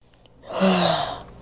短声叹气
描述：手机录制的音效
标签： 叹气
声道立体声